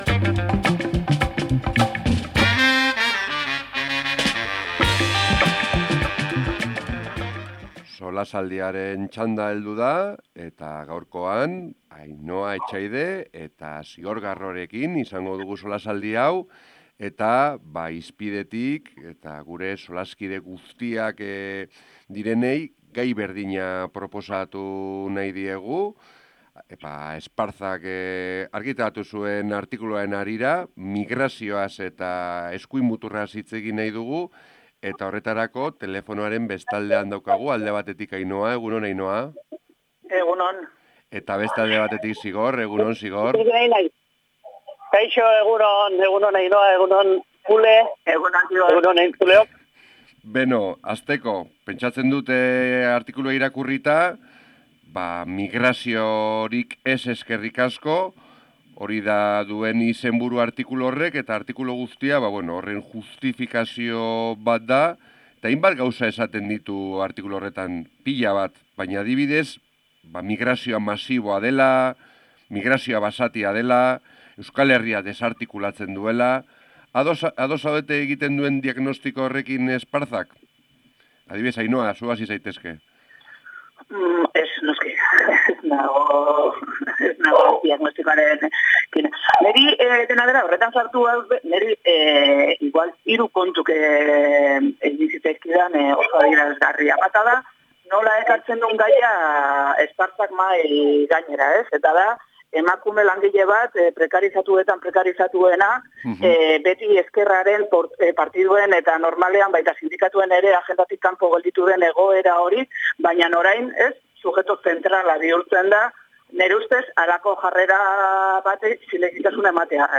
Solasaldia | Migrazioa eta eskuin muturra | Hala Bedi